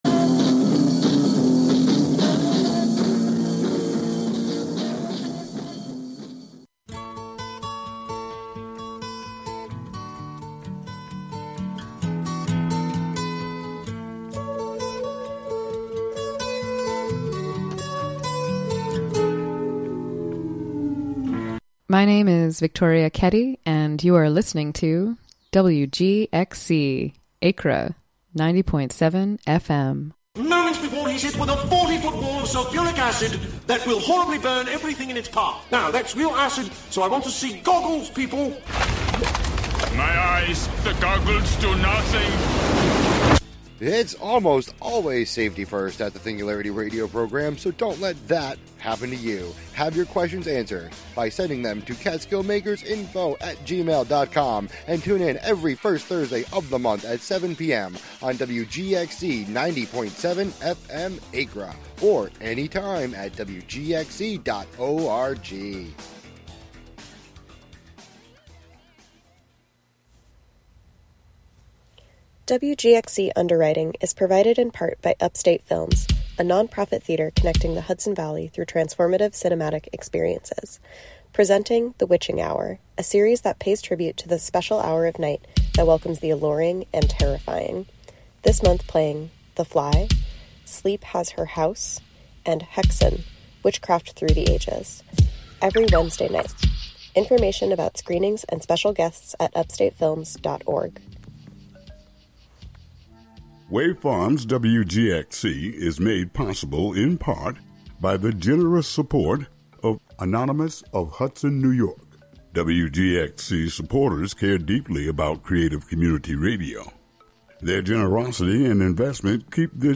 On this monthly program, “La Ville Inhumaine” (The Inhuman City), you will hear music, found sounds, words, intentional noise, and field recordings all together, all at once.